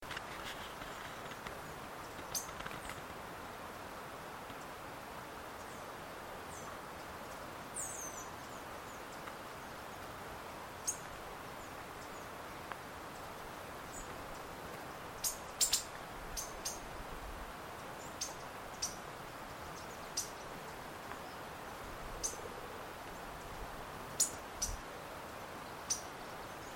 Tietinga (Cissopis leverianus)
Contacto de una bandadita.
Nome em Inglês: Magpie Tanager
Localidade ou área protegida: Reserva Privada San Sebastián de la Selva
Condição: Selvagem
Frutero-Overo.mp3